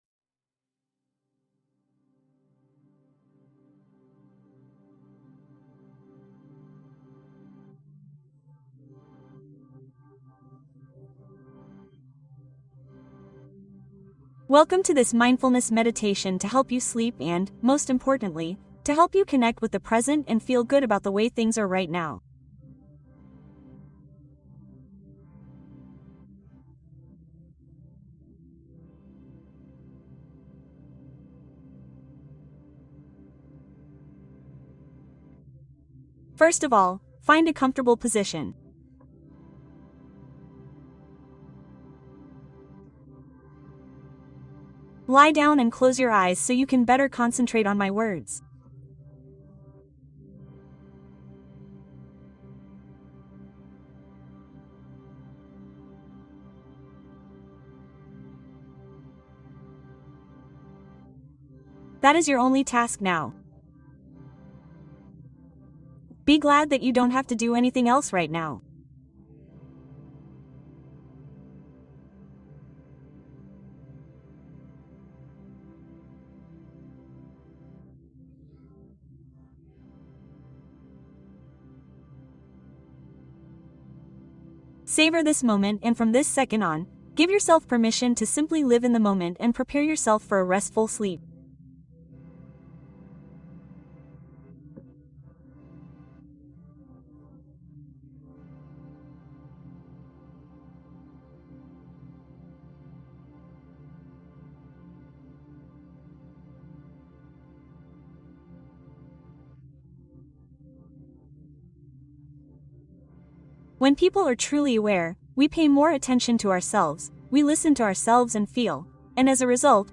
Quince minutos de mindfulness para preparar un descanso profundo